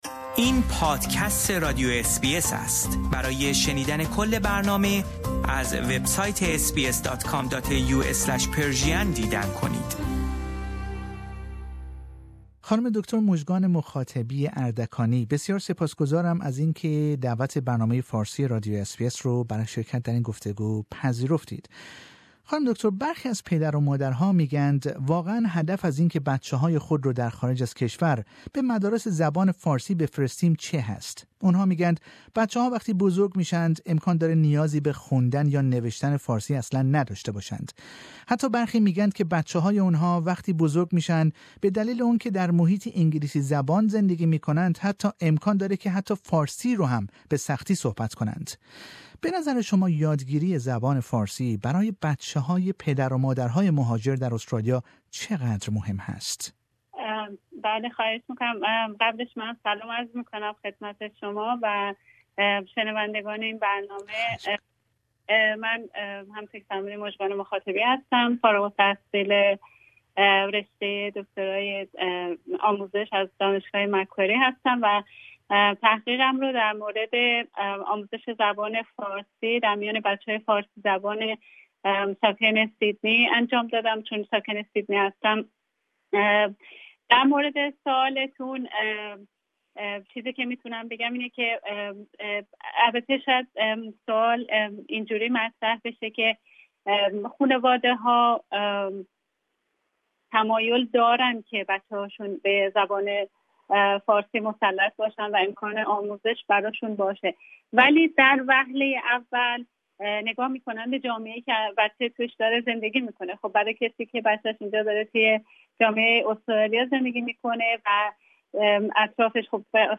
This interview is not available in English.